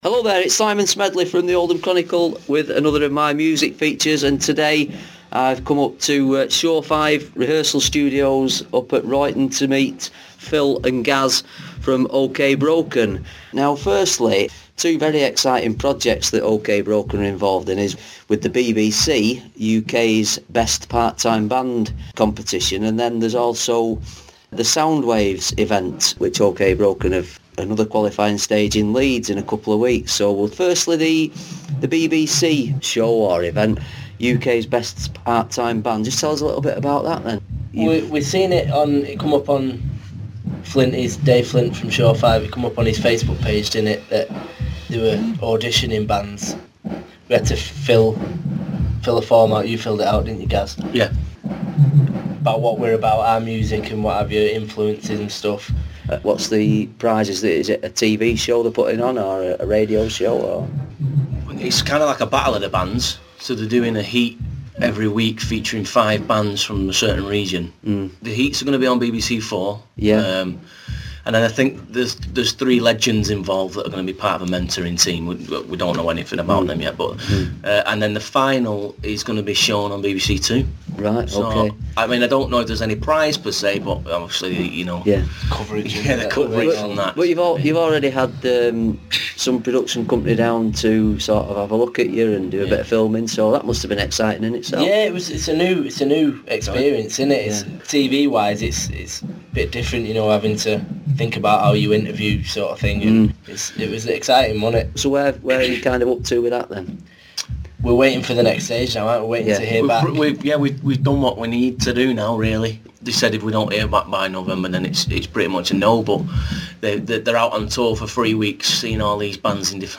in conversation